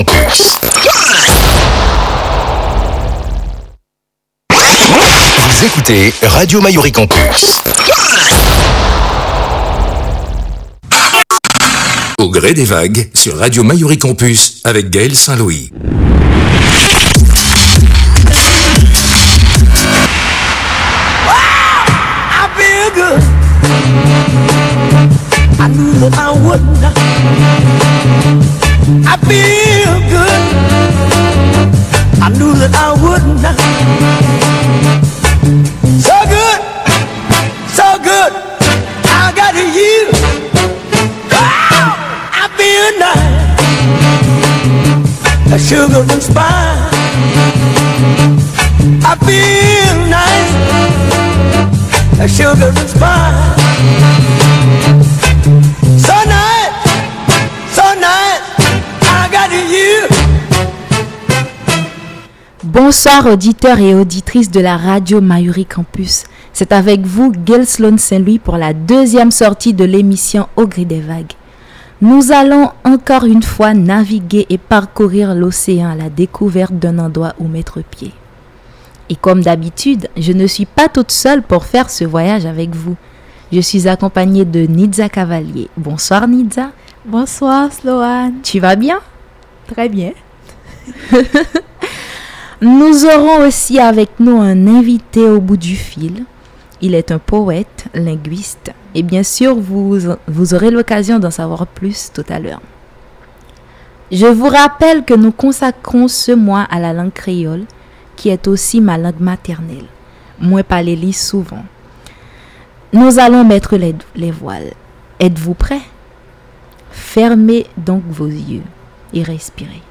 Par téléphone, avec son invité